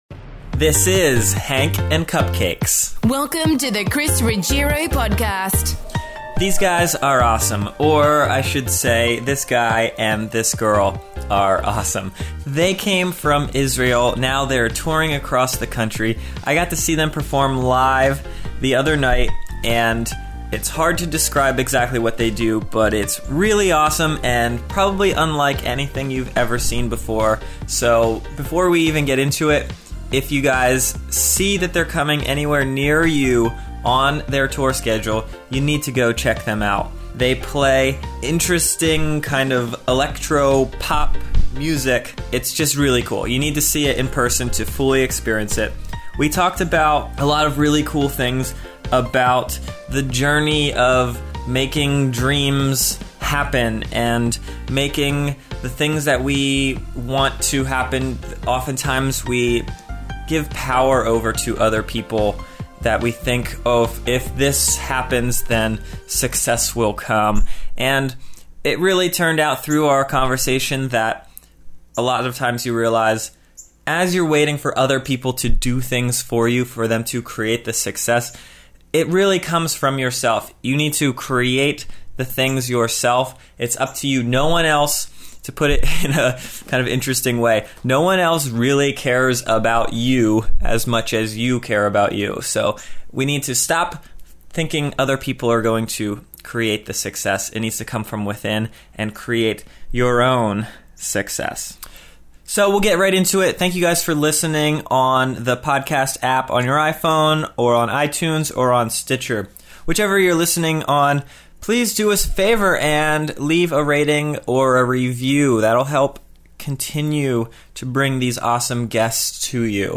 Drums, Bass. Pop duo from Brooklyn via Isreal.